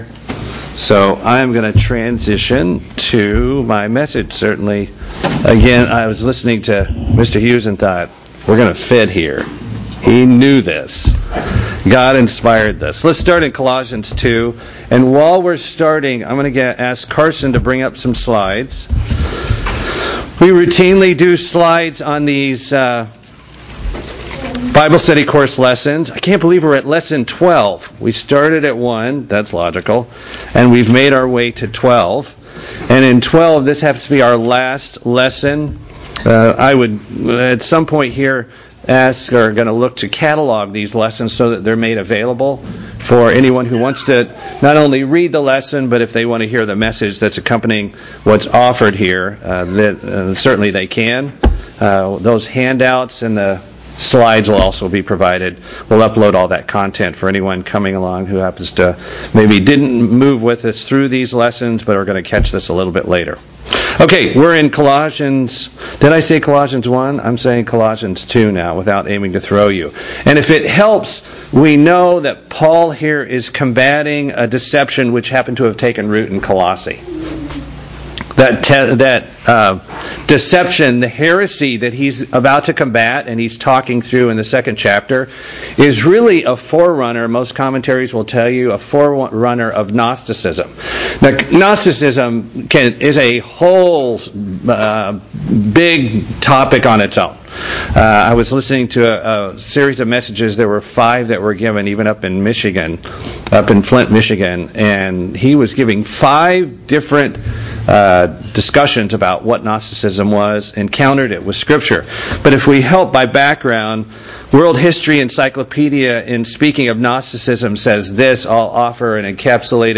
[Note - This message was originally given on 2/18/25 in Buford without video, but updated to the one given in Atlanta on 2/25/23, which was webcast.]